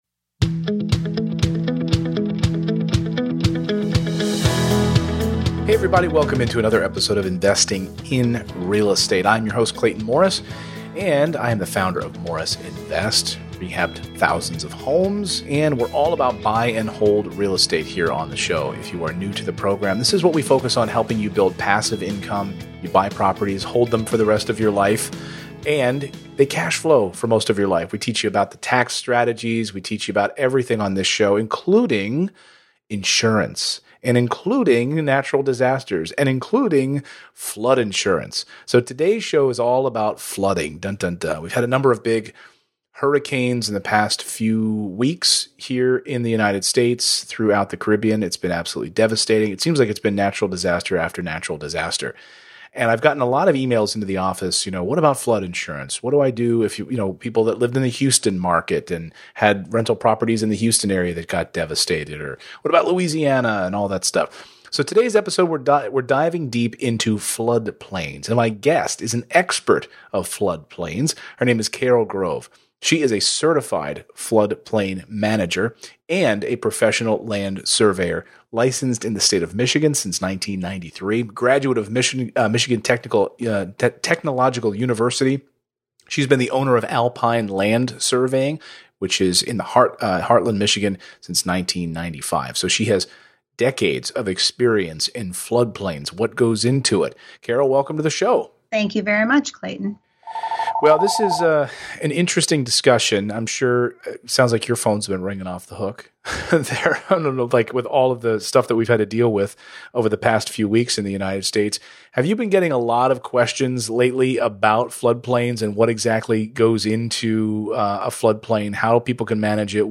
EP213: Understanding Flood Plains - Interview